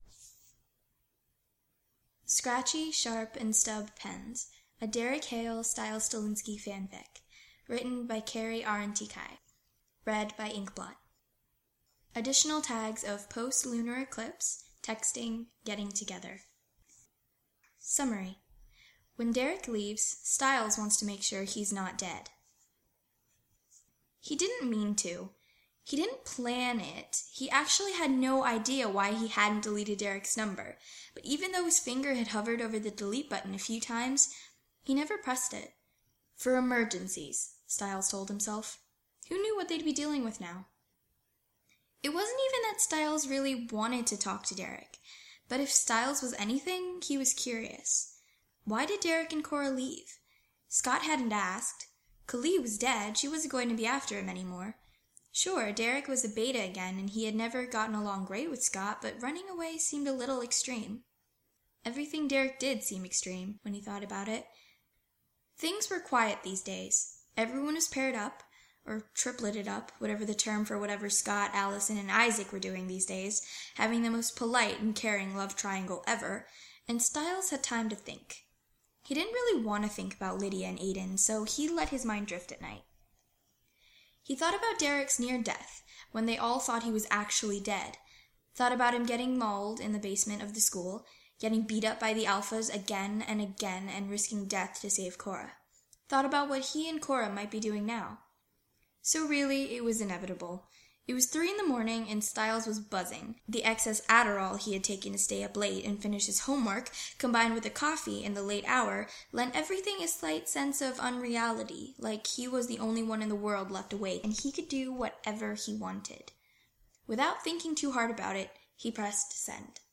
[podfic] Scratchy
When Derek leaves, Stiles wants to make sure he's not dead. Notes: Inspired by Scratchy, Sharp and Stub Pens by KeriArentikai Well, I'm still working on the different voices for different characters thing, but I'm getting there... possibly.